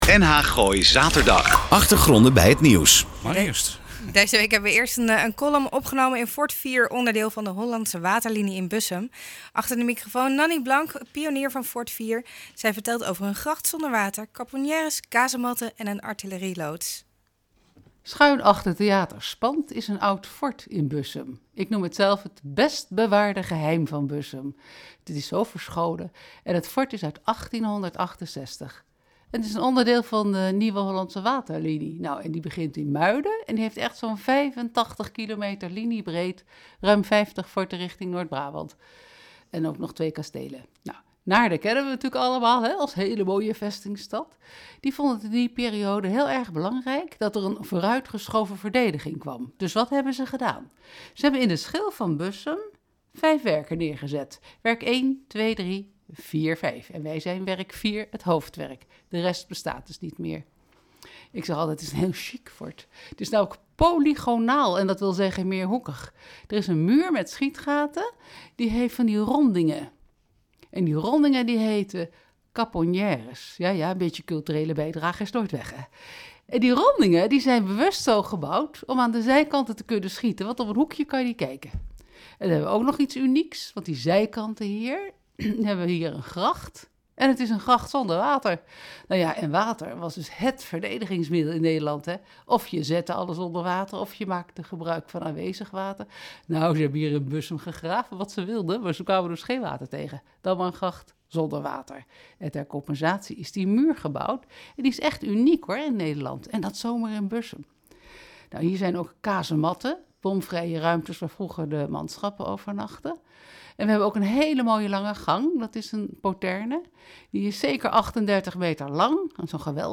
Deze week een column, opgenomen in Fort IV, onderdeel van de Hollandse Waterlinie, in Bussum.